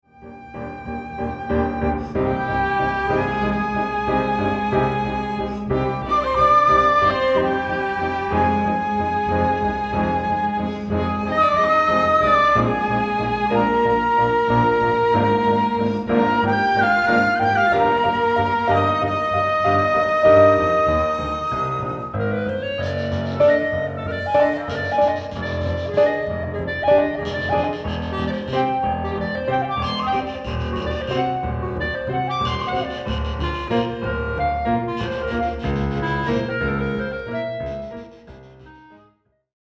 Tango
chamber group
flute
clarinet
violin
cello
piano
bass